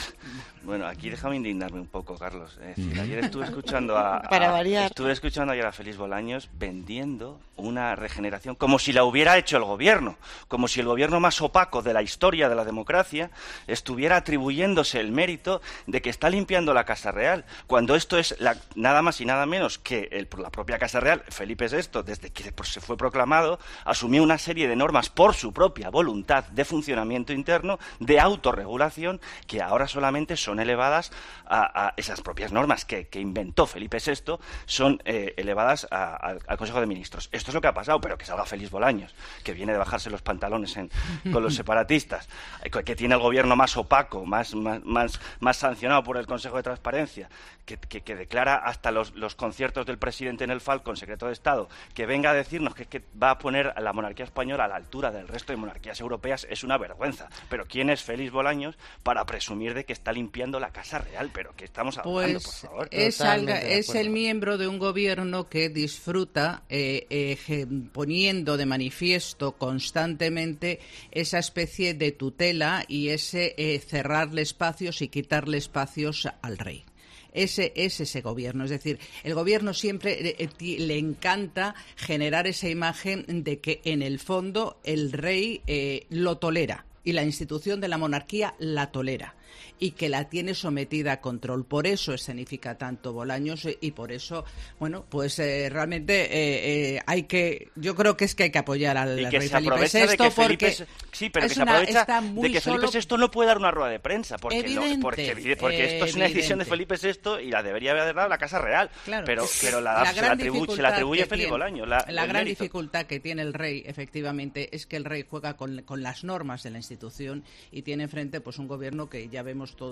El colaborador de 'Herrera en COPE' ha mostrado su indignación con la postura del ministro
"Déjame indignarme un poco, Carlos", ha comenzado pidiendo Jorge Bustos en el café de redacción de 'Herrera en COPE'.
Es una decisión de Felipe VI, pero se la atribuye Félix Bolaños", cargaba el periodista y colaborador de COPE en un café de redacción agitado.